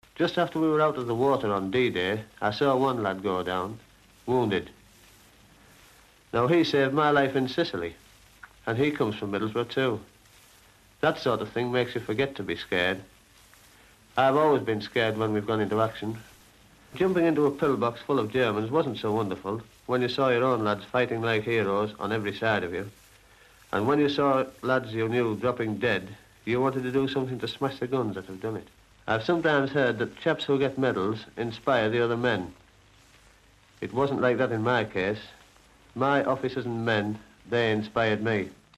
Sgt Maj Stan Hollis from Middlesbrough was the only person to be awarded the Victoria Cross for acts of bravery during the D-Day landings. He was interviewed by the BBC after he single-handedly charged a German machine gun position on Gold Beach and captured the men inside. Find out what inspired him.